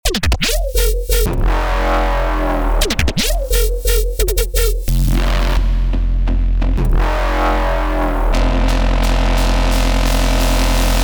DNB SERUM PRESETS
LOOP PACK